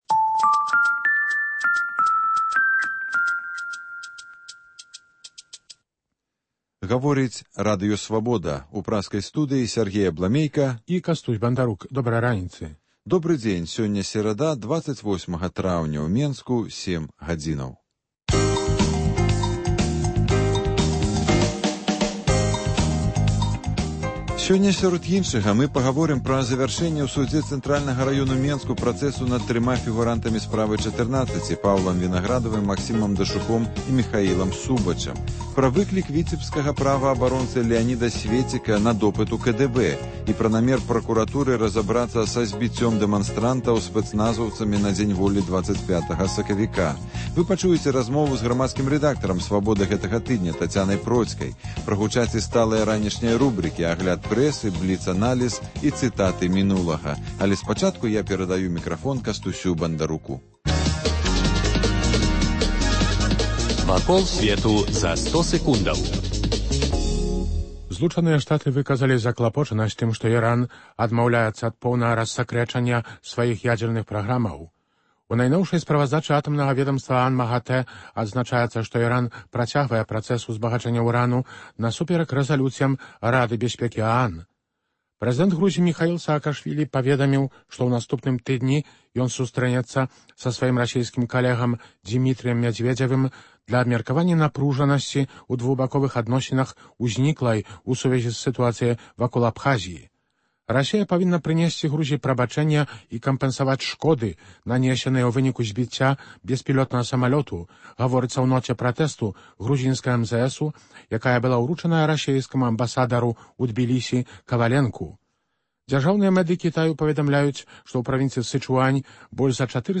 Ранішні жывы эфір
А калі насамрэч зьявілася яна на заходніх межах краіны, калі мець на ўвазе ВКЛ? Інтэрвію з гарадзенскім гісторыкам.